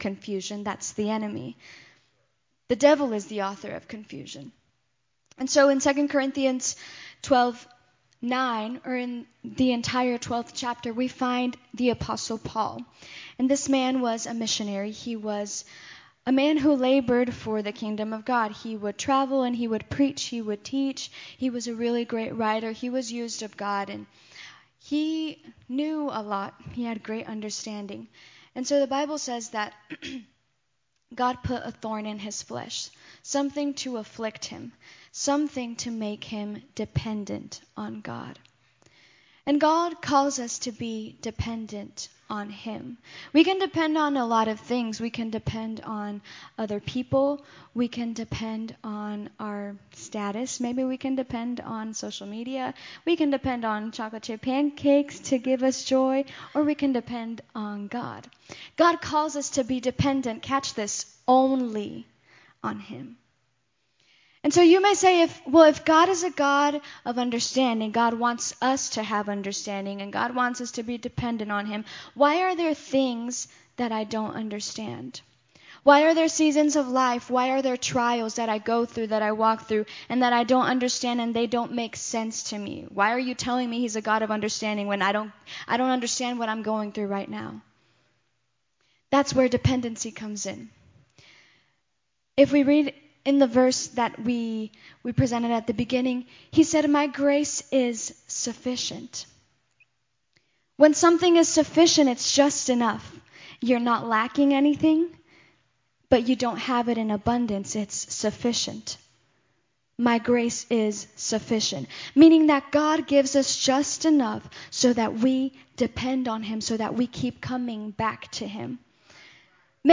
Guests from IBC speakers and worship